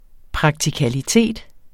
Udtale [ pʁɑgtikaliˈteˀd ]